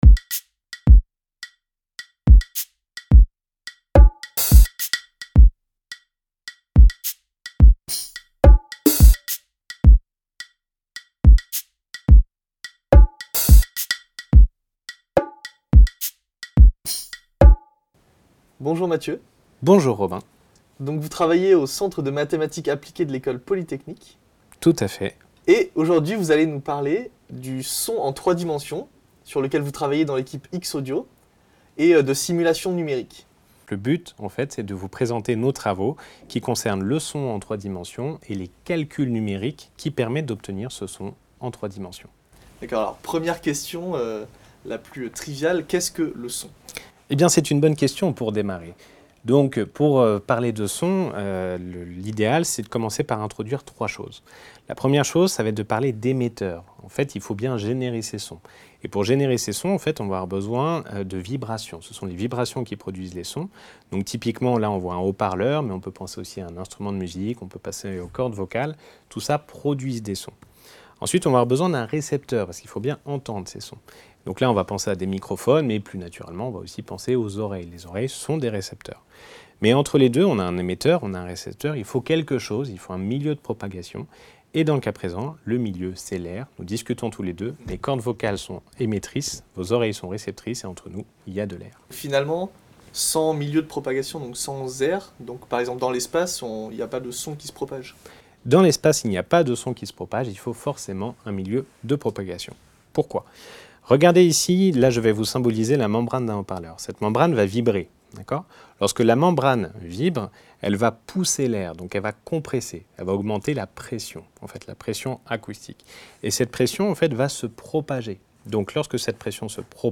Léçon de son en trois dimensions, la simulation numérique au service du son 3D | Canal U